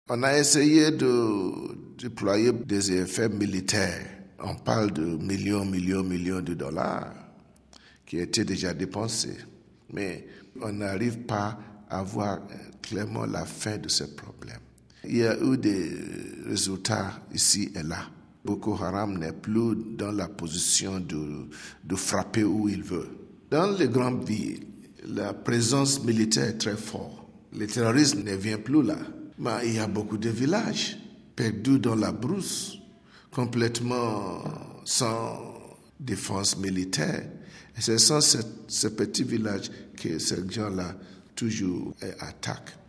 Le cardinal Onayekan : RealAudioMP3